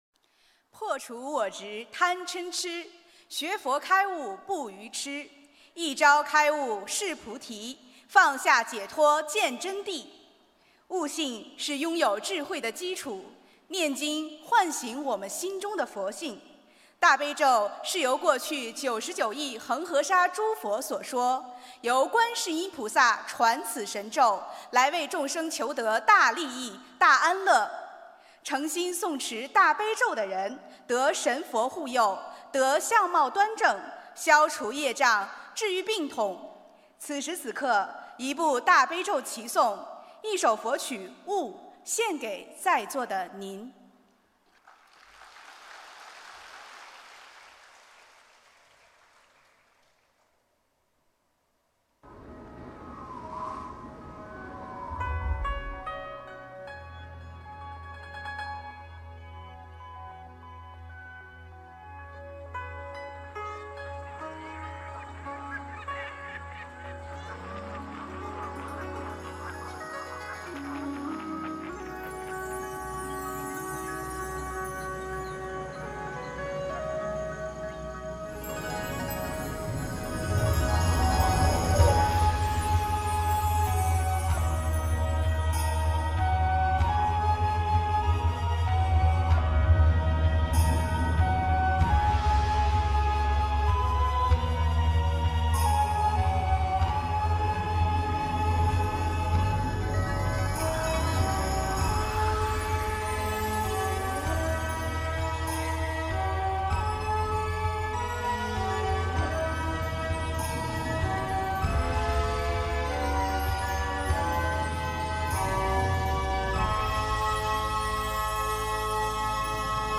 音频：新加坡佛友合诵《大悲咒》一曲一天堂、一素一菩提！